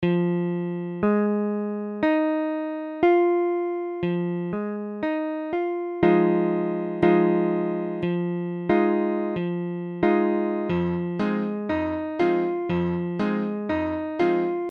Tablature Fm7.abcFm7 : accord de Fa mineur septième
Mesure : 4/4
Tempo : 1/4=60
A la guitare, on réalise souvent les accords en plaçant la tierce à l'octave.
Forme fondamentale : tonique quinte septième mineure tierce mineure
Fm7.mp3